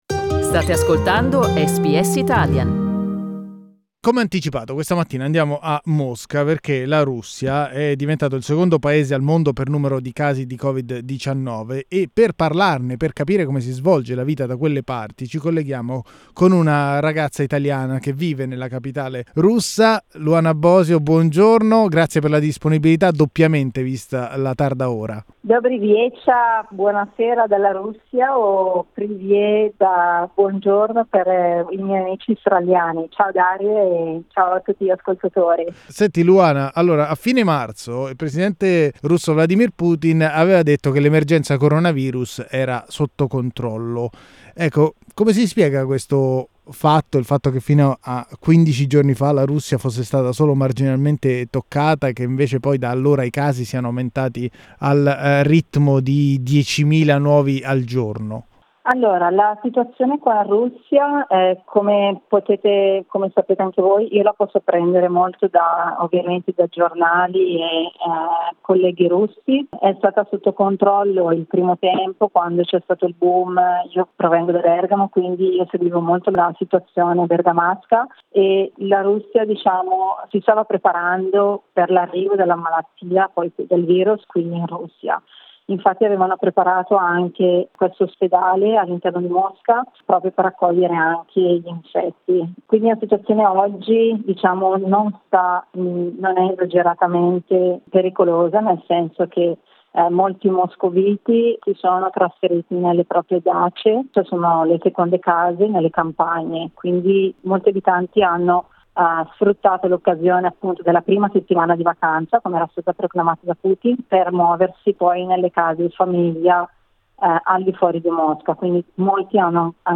La testimonianza